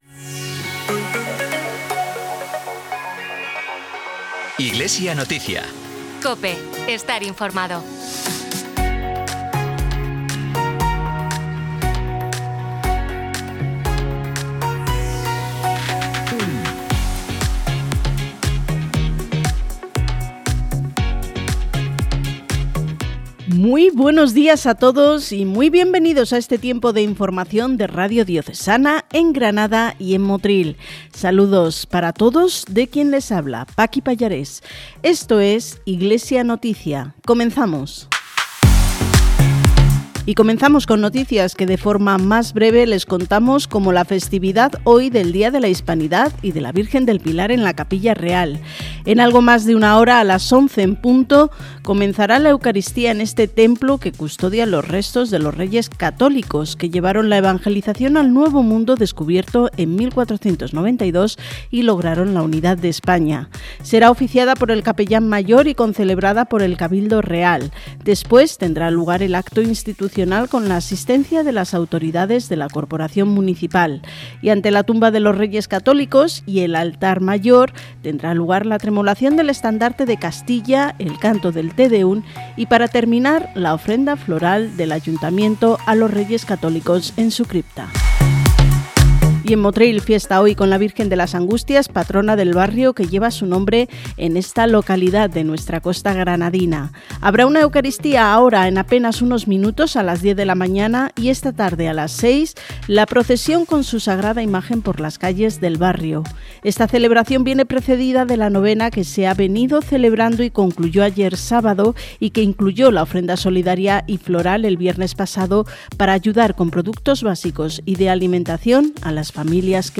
Programa emitido en COPE Granada y COPE Motril el 12 de octubre de 2025, Solemnidad de la Virgen del Pilar y Día de la Hispanidad.